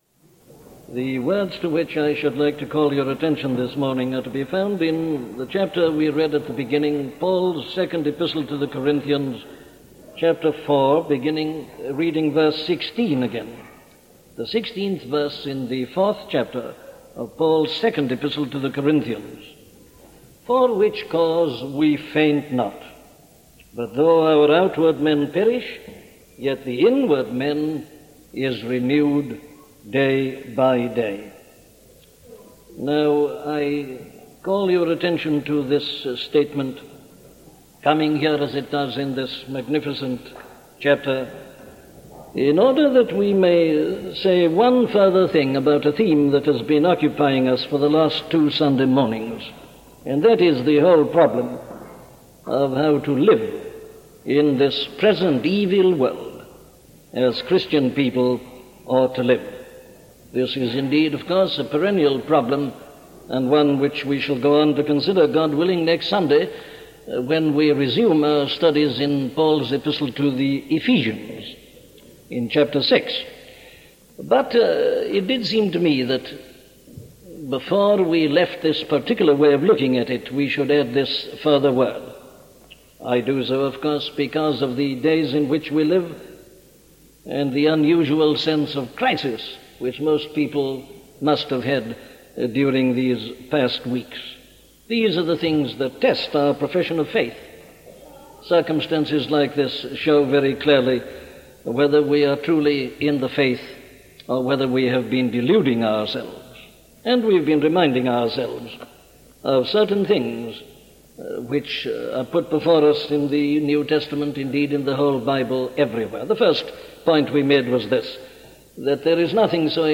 The Inward Man - a sermon from Dr. Martyn Lloyd Jones
Scripture